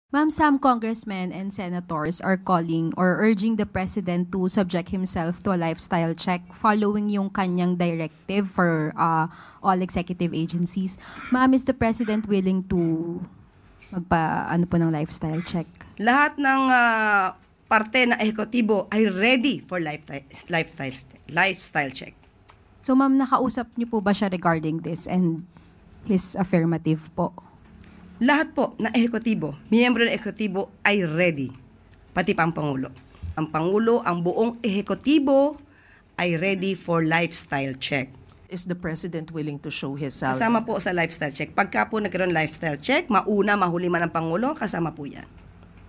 ayon kay PCO Undersecretary Atty. Claire Castro sa isang press briefing ngayong Biyernes, Agosto 29, 2025.